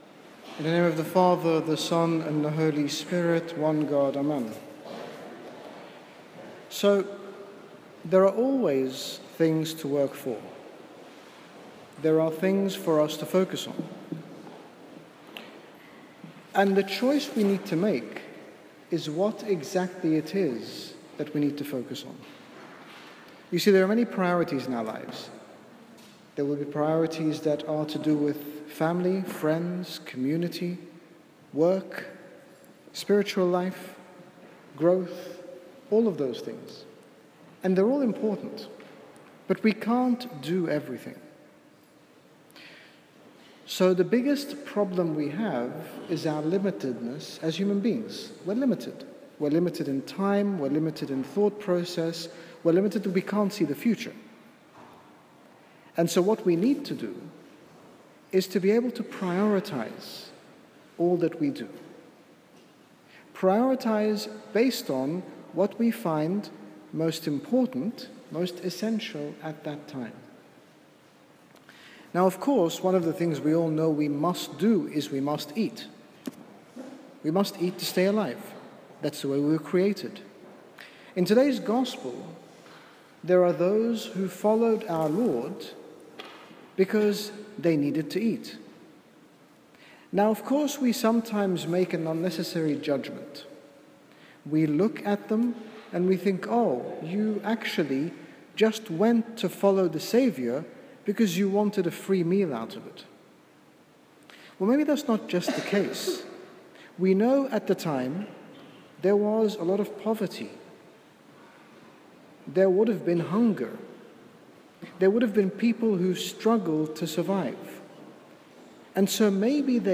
sermon
In this short sermon, His Grace Bishop Angaelos, General Bishop of the Coptic Orthodox Church in the United Kingdom, speaks to us about seeing God for Who He is, and not only asking for miracles, or for Him to provide, but actually seeing Him.